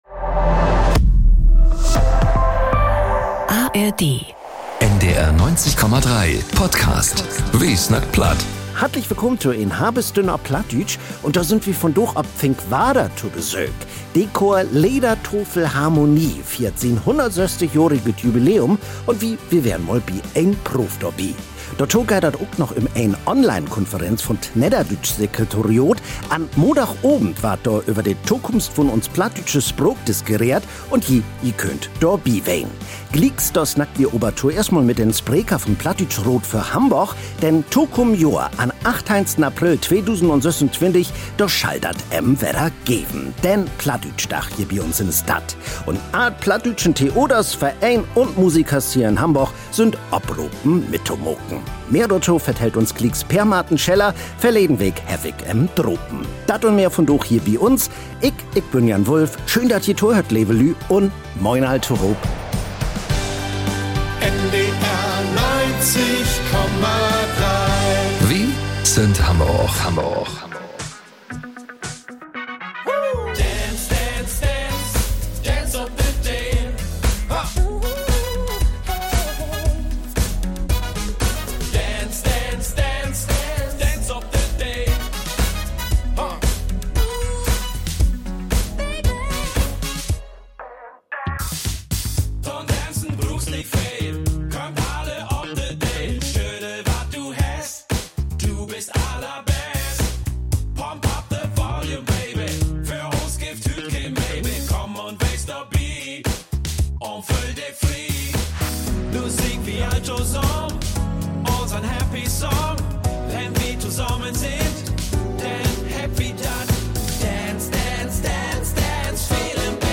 Snacks, Reportagen un Musik: Wat in Hamborg un ümto in de plattdüütsche Welt passeren deit un över wat in Kultur, Politik un Sport op Platt snackt warrt - Ji kriegt dat mit bi "Wi snackt Platt" op NDR 90,3.
Wi weern vöraf mal bi en Proov dorbi.